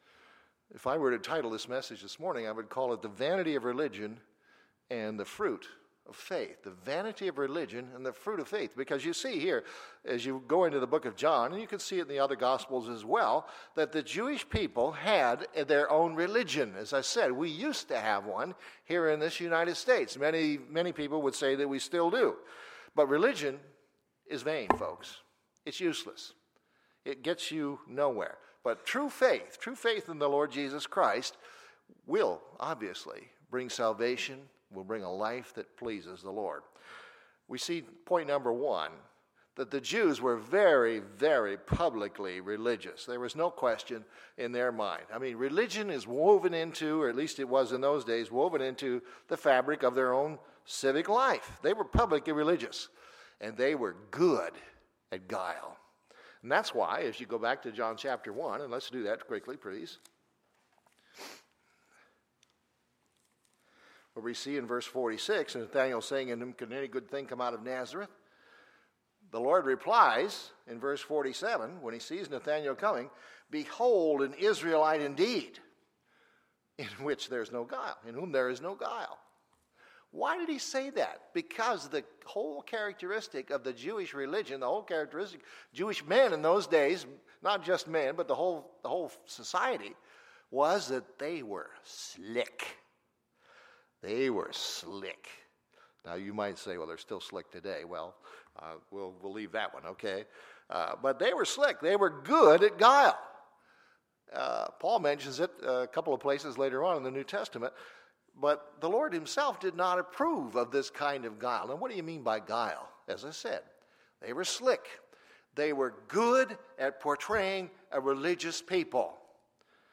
Sunday, July 31, 2016 – Sunday Morning Service